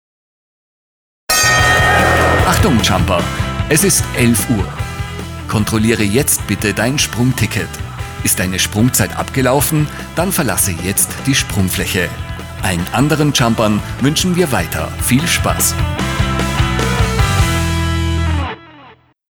Gleichzeitig können wir für Sie im hauseigenen Tonstudio Werbe-Spots mit Profi-Sprecher und speziellem Mastering für große Räume anbieten.
Mit BroadCastl können Sie Durchsagen nach Uhrzeit steuern. So können Sie alle Jumper daran erinnern, neue Tickets zu lösen, wenn das Jump-Intervall zu Ende ist.